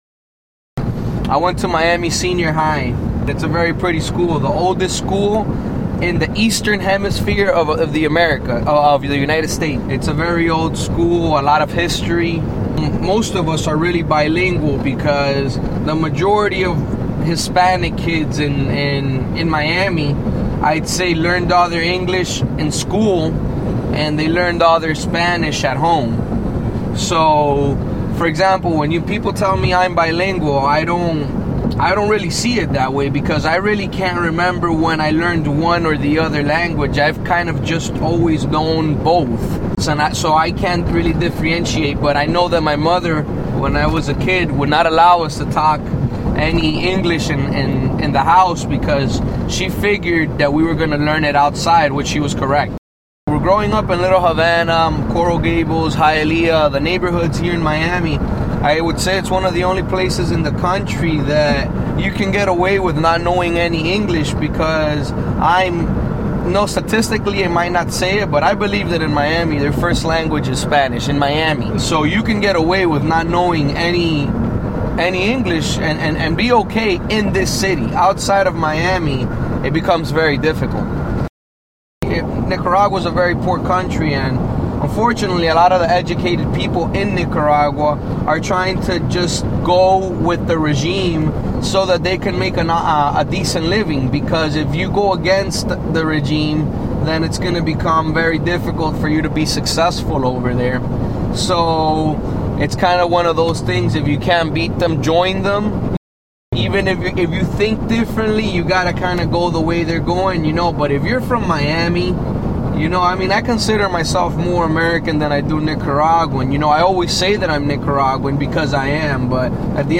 Sjanger: Rhythmic Soul